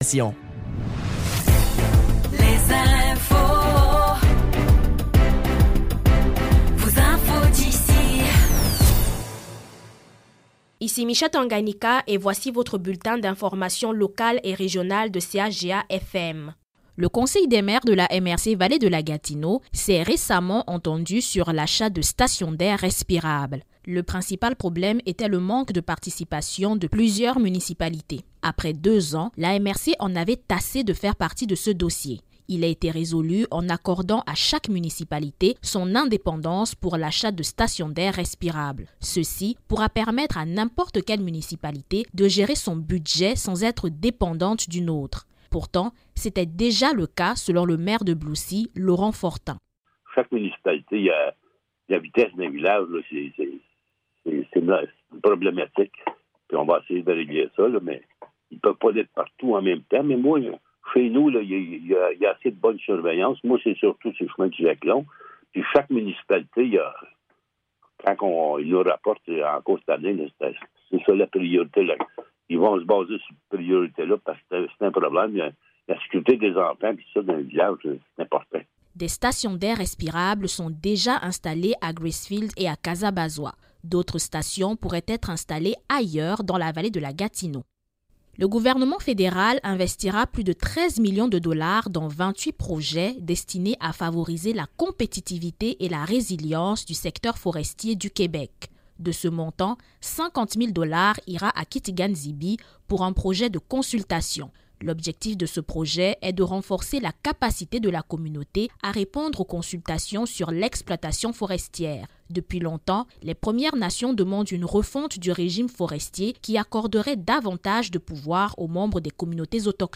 Nouvelles locales - 27 mars 2025 - 12 h